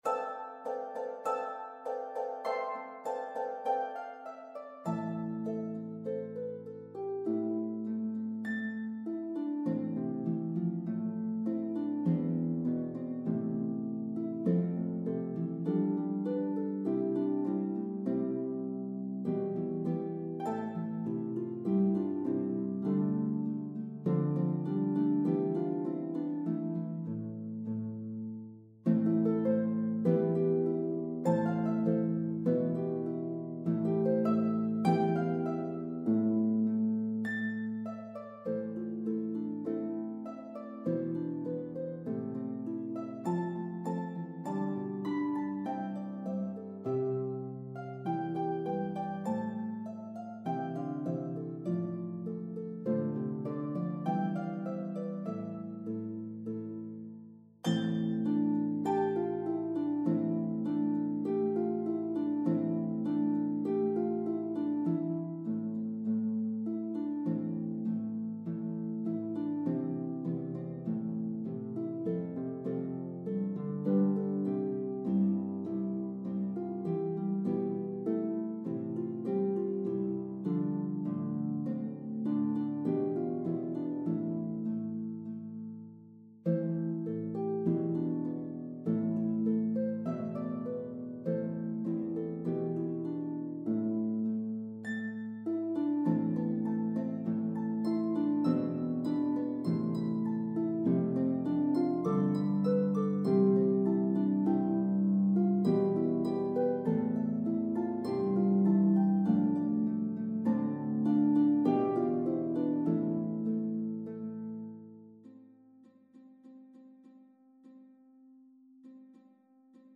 Intermediate pedal or lever